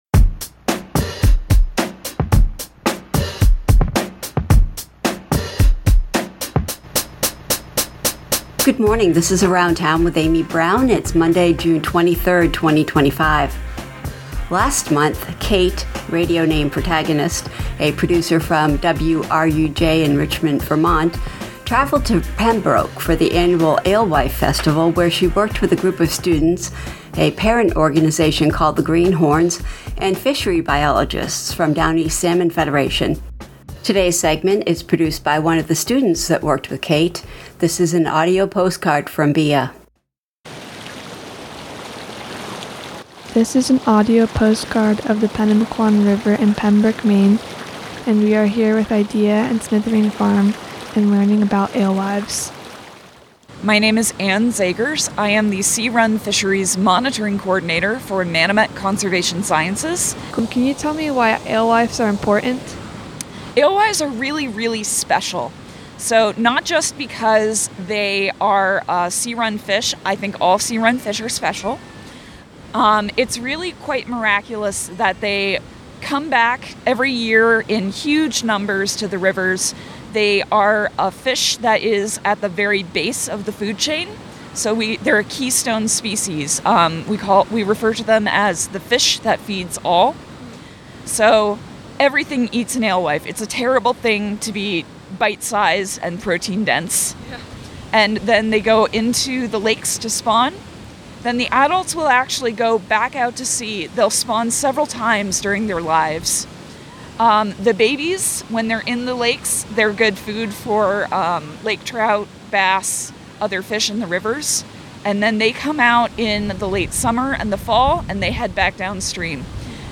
An audio postcard from the Alewives Festival in Pembroke, Maine in May 2025.